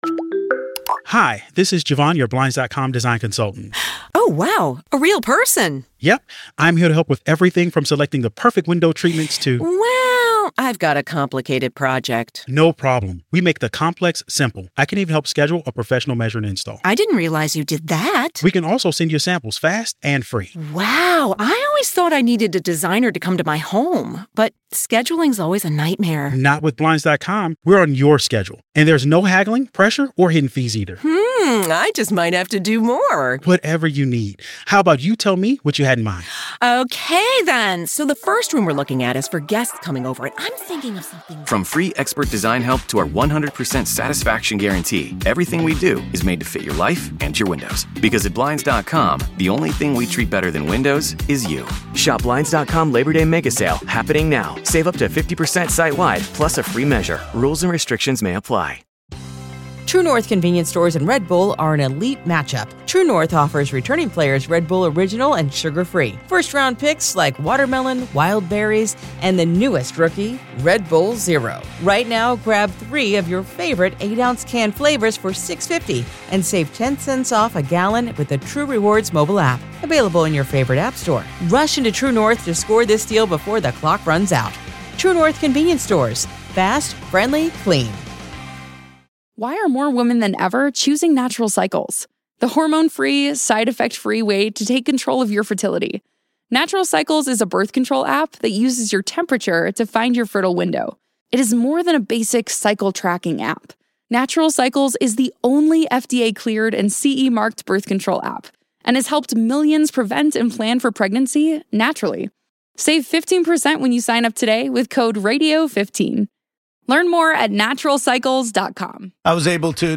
Lugash Debate Series